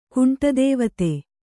♪ kuṇṭadēvate